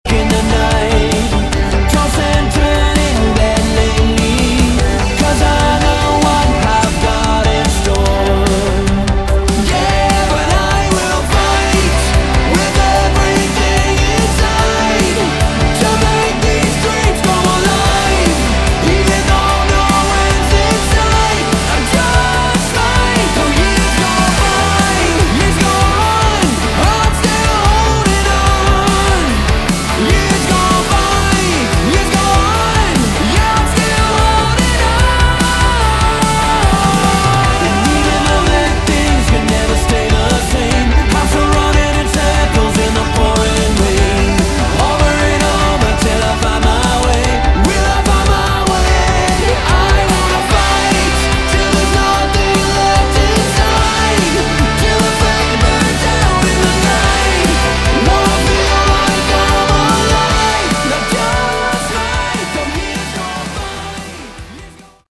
Category: AOR
Vocals, Guitars, Synths
Drums, Percussion